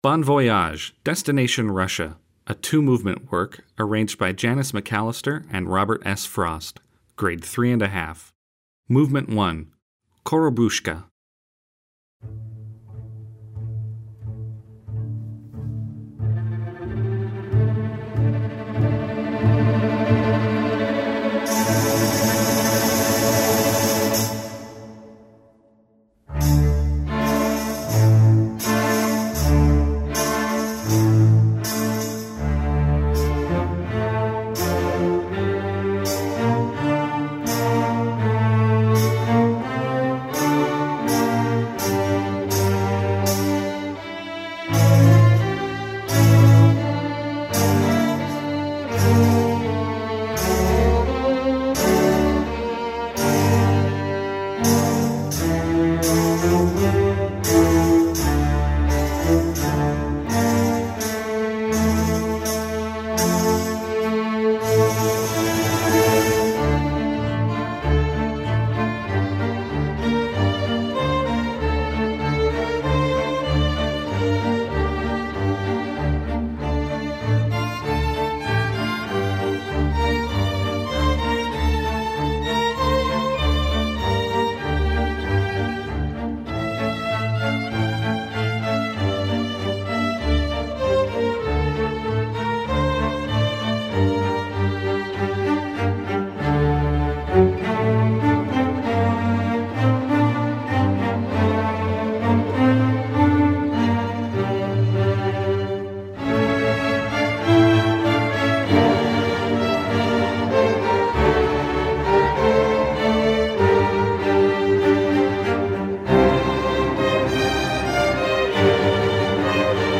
Composer: Russian Folk Song
Voicing: String Orchestra W